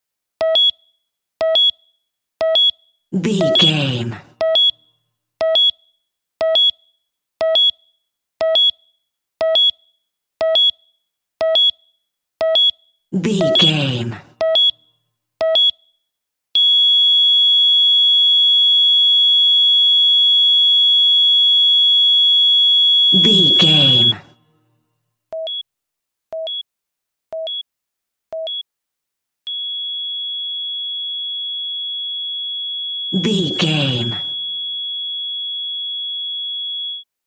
Emergency Heartbeat Monitor Double Beeps Flat
Sound Effects
chaotic
anxious
dramatic